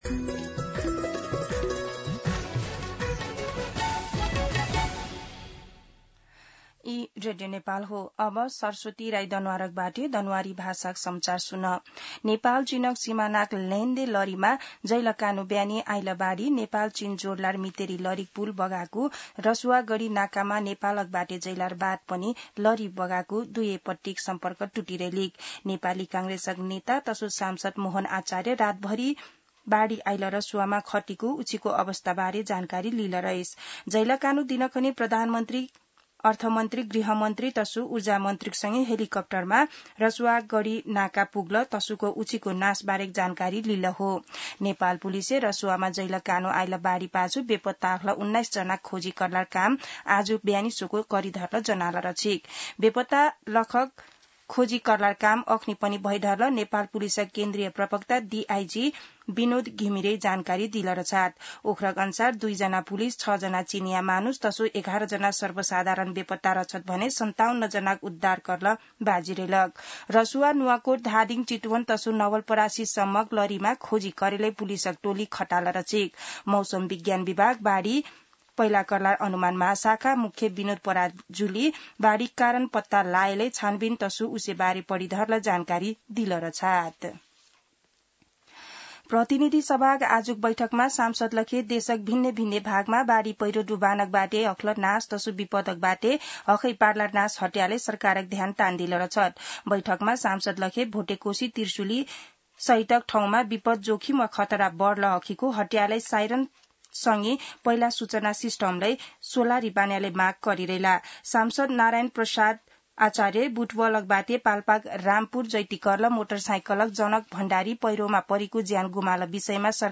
दनुवार भाषामा समाचार : २५ असार , २०८२
Danuwar-News-3-25.mp3